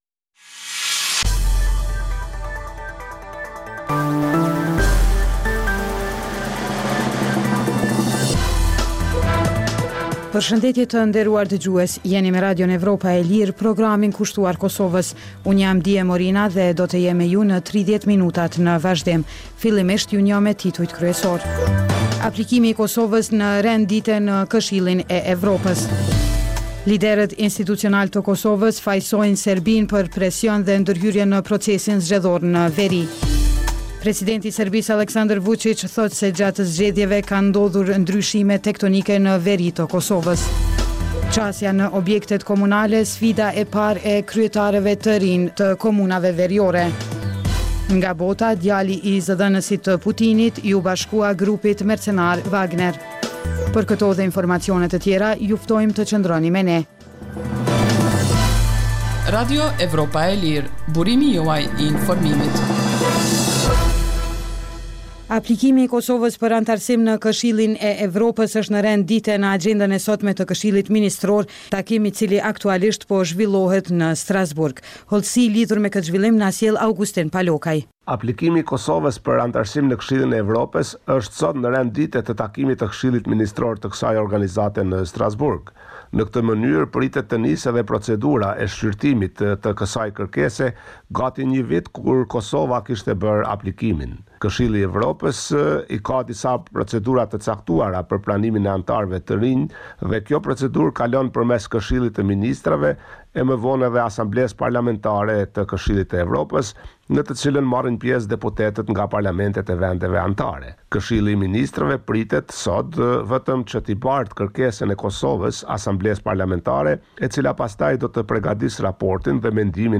Emisioni i orës 16:00 është rrumbullaksim i zhvillimeve ditore në Kosovë, rajon dhe botë. Rëndom fillon me kronikat nga Kosova dhe rajoni, dhe vazhdon me lajmet nga bota. Kohë pas kohe, në këtë edicion sjellim intervista me analistë vendorë dhe ndërkombëtarë për zhvillimet në Kosovë.